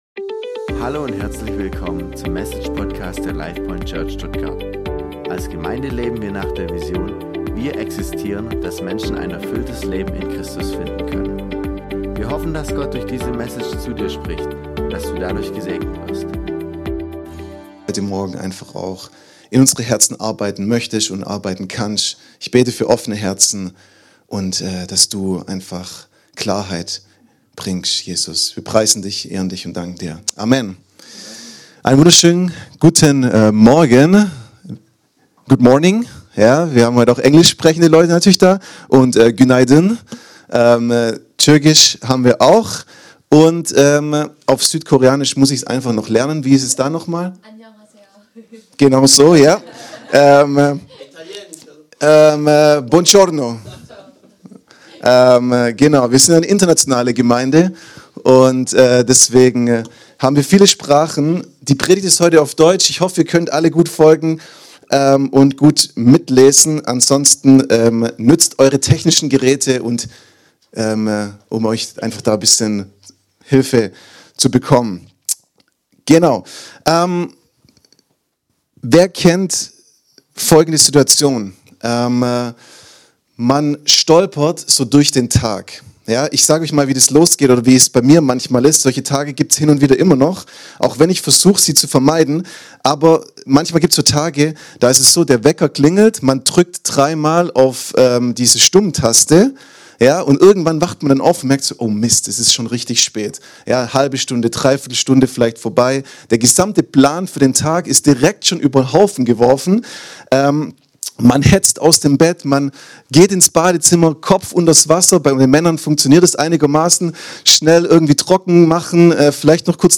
In dieser Predigt